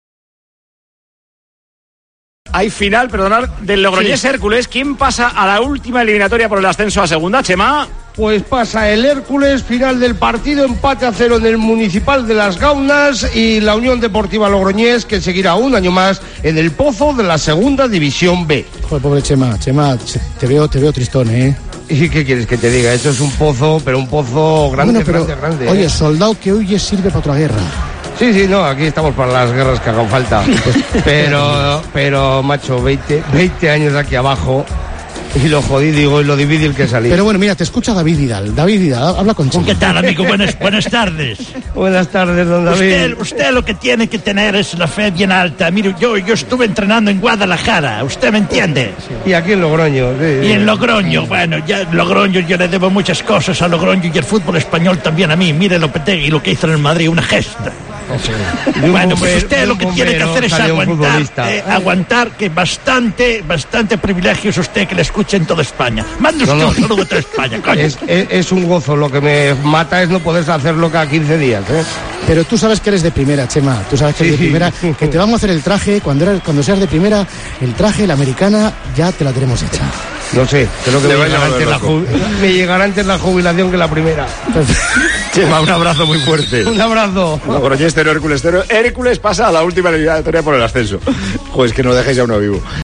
El toque de humor de este pasado sábado en antena lo ha puesto el Grupo Risa tras caer eliminado la UD Logroñés.